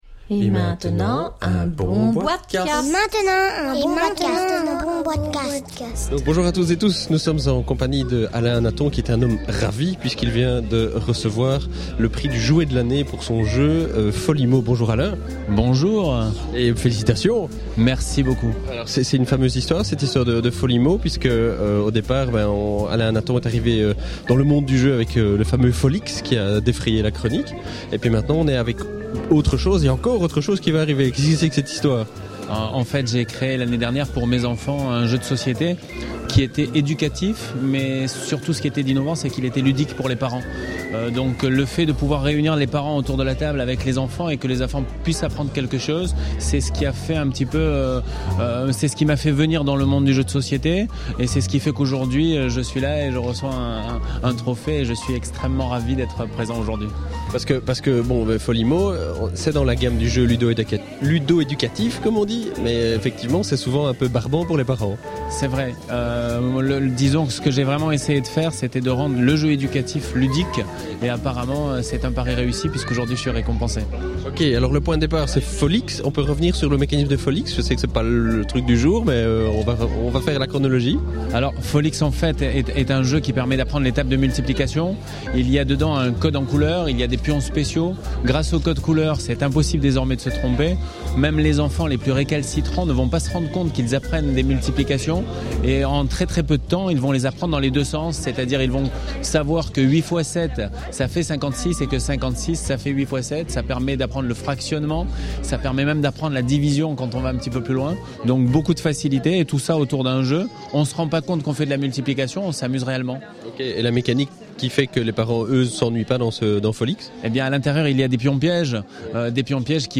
(enregistré lors de la conférence de presse du « Jouet de l’année » édition 2009)